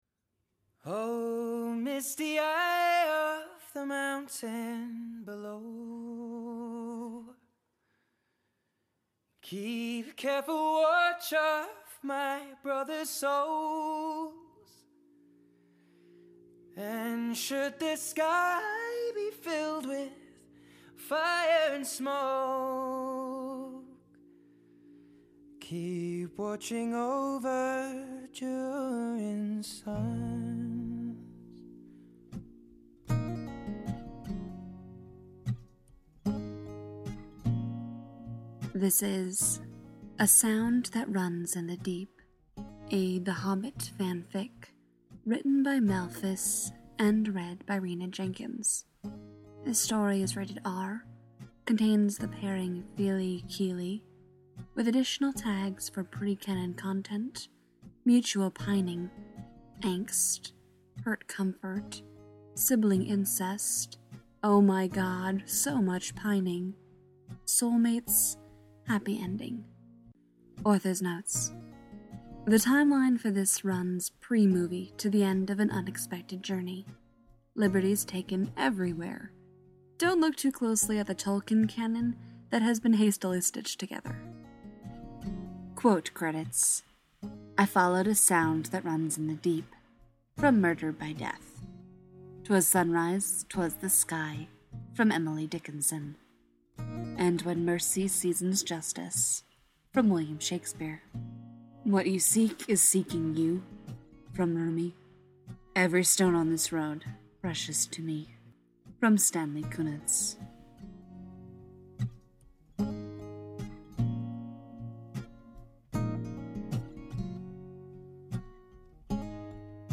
info: collaboration|ensemble , info|improvisational podfic